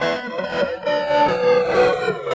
47 GUIT 1 -R.wav